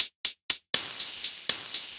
UHH_ElectroHatC_120-05.wav